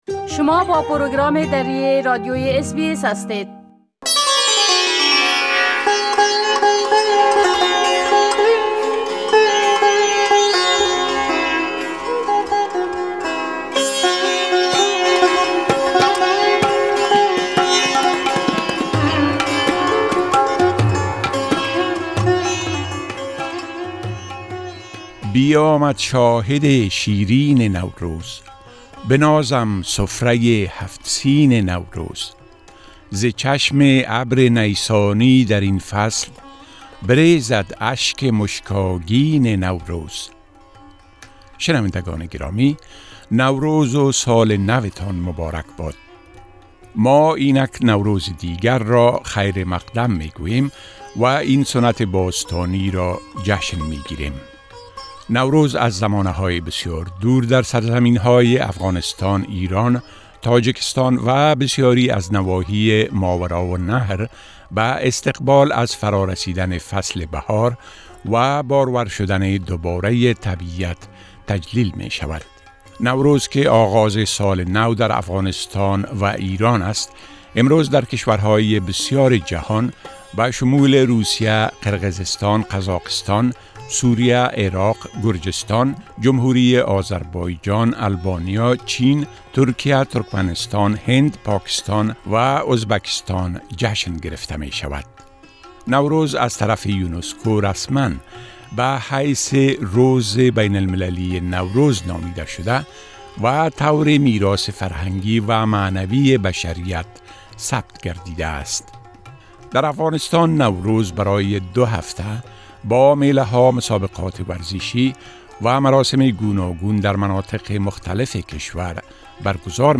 ما با وى مصاحبە كردە ايم كە شما را بە شنيدن آن دعوت ميكنيم